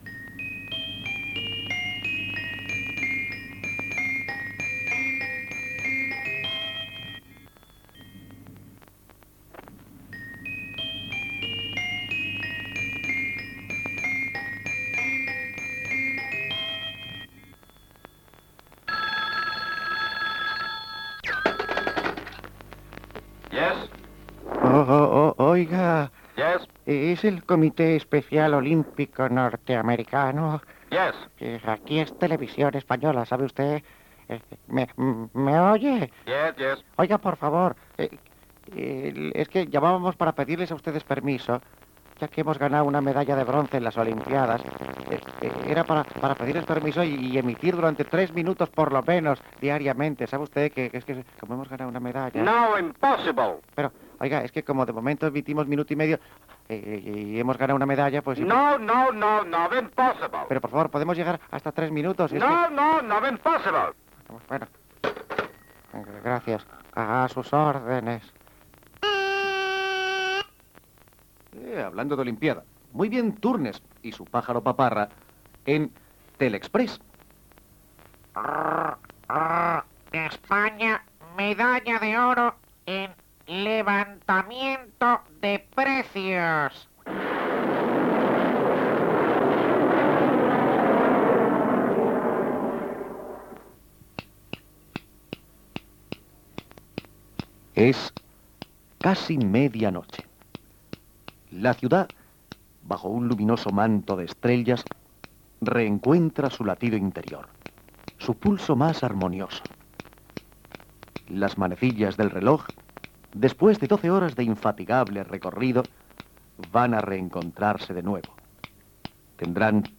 31ace683b597133cbe53ec7f4e9dc6127c6db3d8.mp3 Títol Radio Juventud RJ2 Emissora Radio Juventud RJ2 Cadena Radiocadena Española Titularitat Pública estatal Nom programa El sacapuntas Descripció Sintonia de Radio Juventud .
Gènere radiofònic Entreteniment